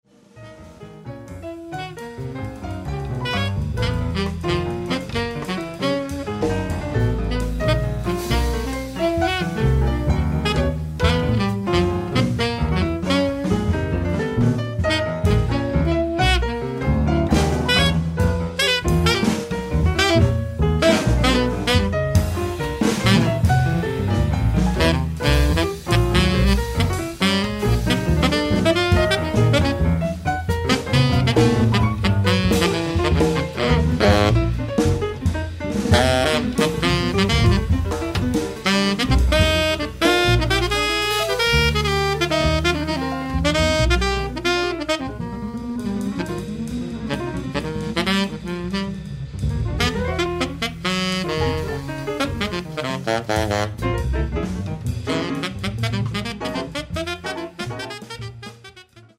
pianoforte
sassofono tenore
contrabbasso
batteria